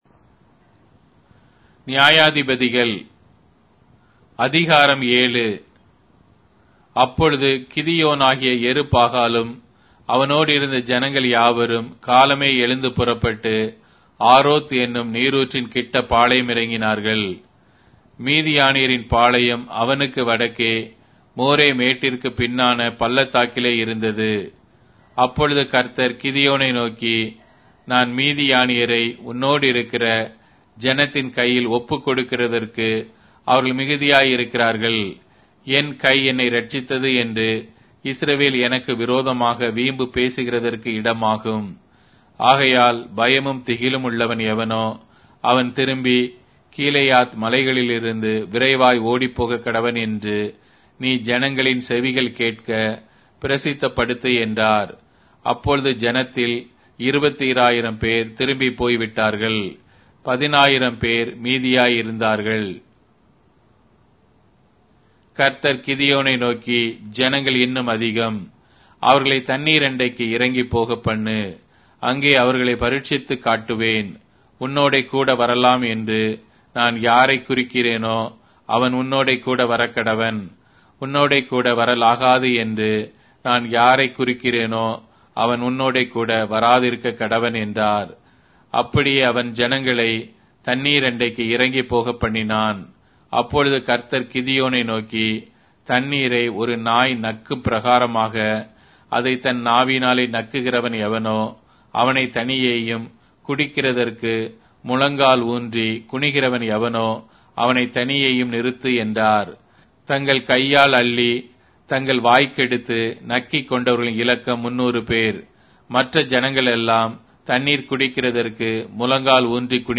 Tamil Audio Bible - Judges 19 in Tov bible version